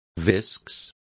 Complete with pronunciation of the translation of viscous.